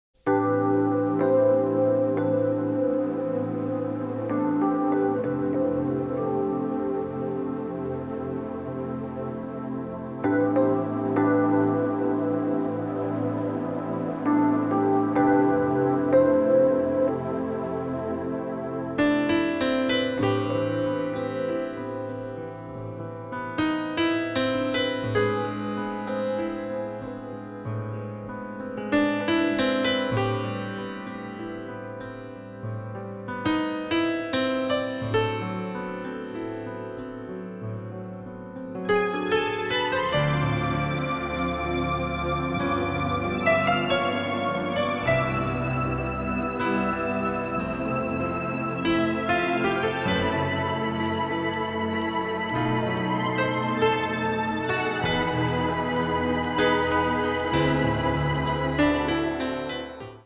piano version
the usual eighties sound of synthesizers
both are in pristine stereo sound.